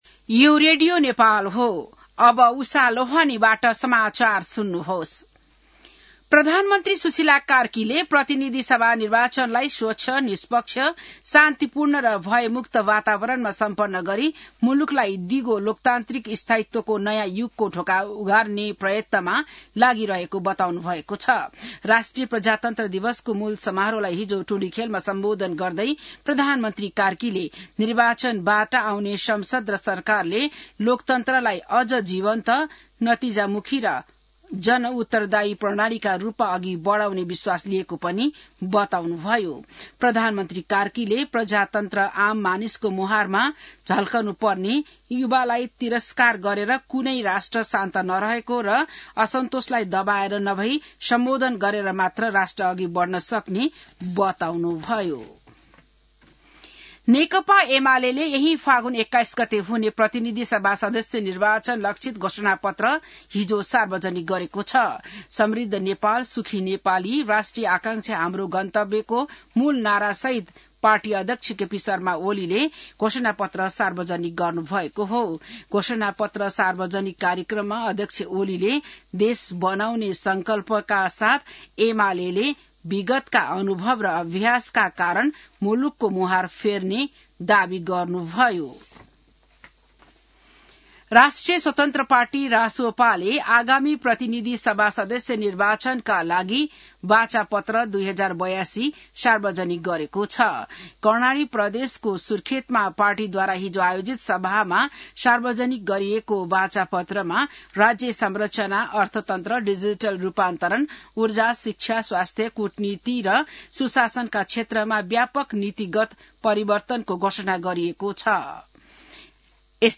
An online outlet of Nepal's national radio broadcaster
बिहान १० बजेको नेपाली समाचार : ८ फागुन , २०८२